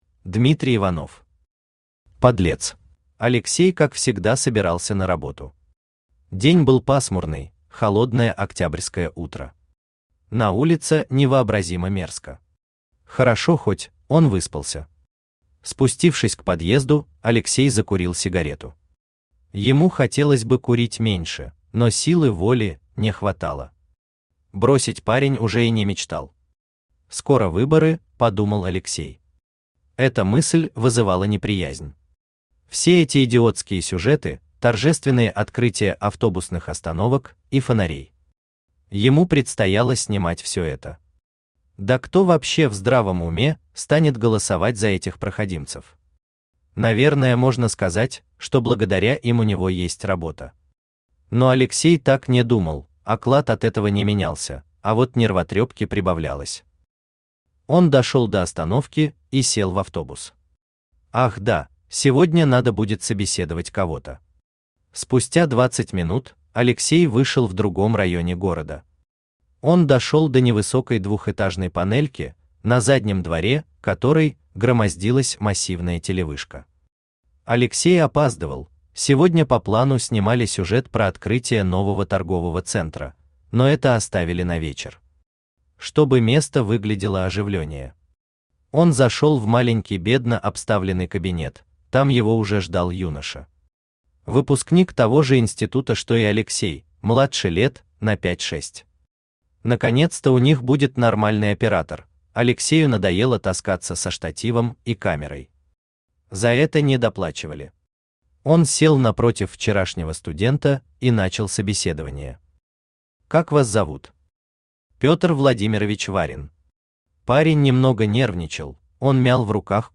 Аудиокнига Подлец | Библиотека аудиокниг
Aудиокнига Подлец Автор Дмитрий Иванов Читает аудиокнигу Авточтец ЛитРес.